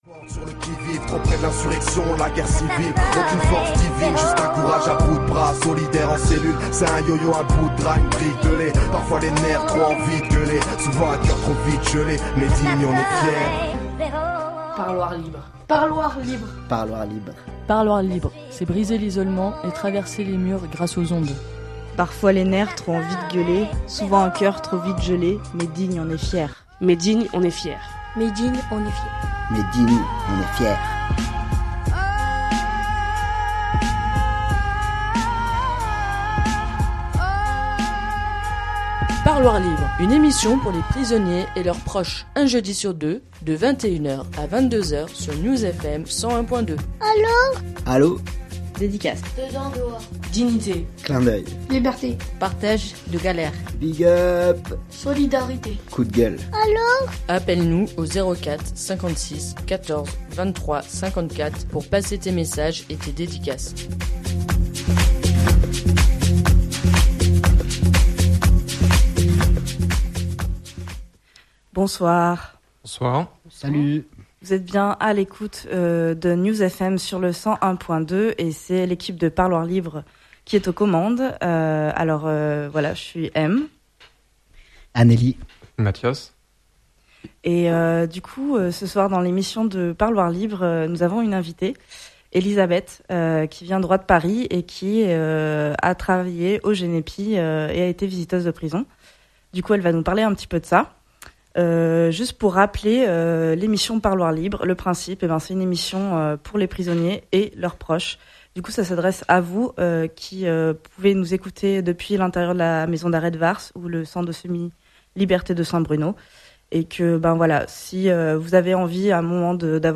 Parloirs Libres est une émission de radio :